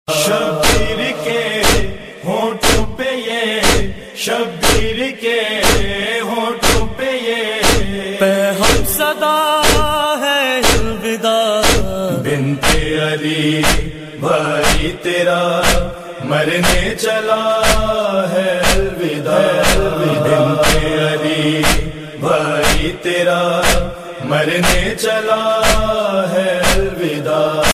Nohay RingTones